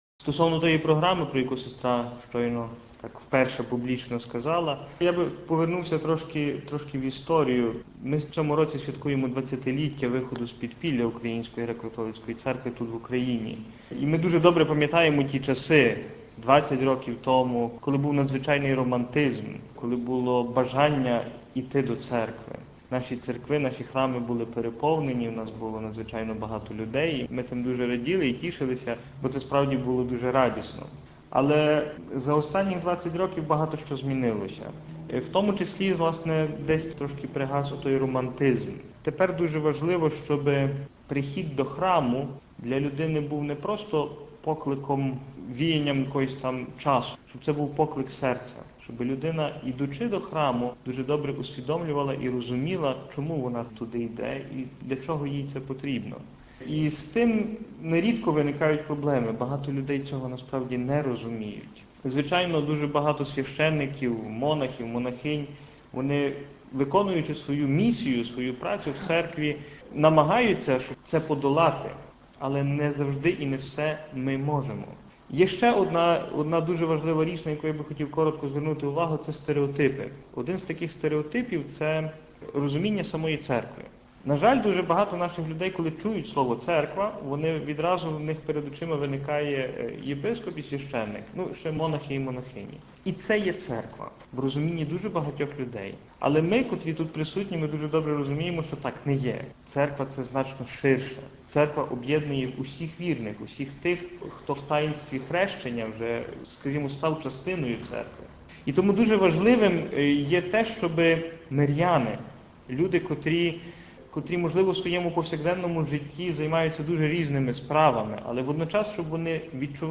Розповідає один із авторів навчальної програми